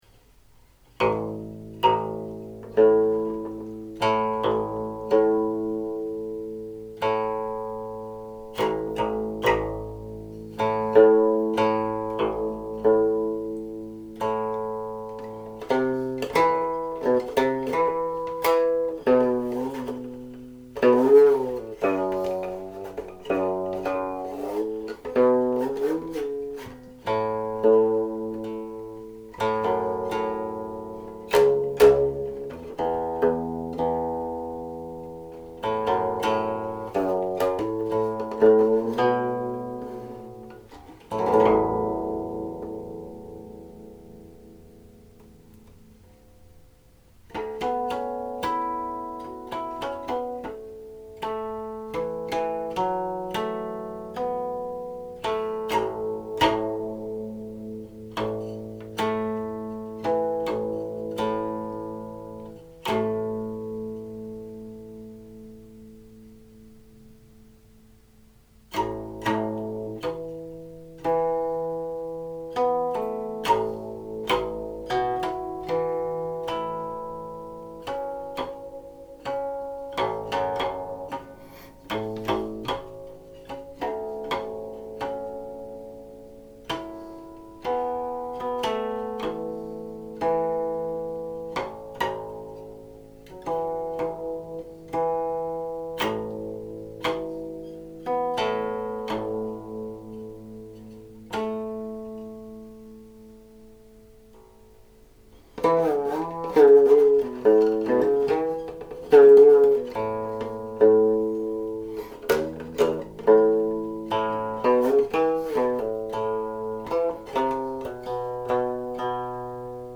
In July 2022 I made four recordings of this Guangling Zhenqu/Guangling San in my Studio for Seeking Solitude (招隱 Zhao Yin Shi) using a Roland Roland R-26 digital recorder with its internal mic.
a qin
but tuned lower